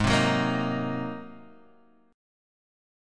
G#9 chord